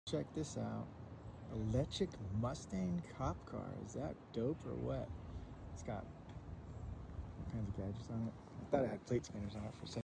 Electric police car. Electric cop sound effects free download
Electric Mustang cop car.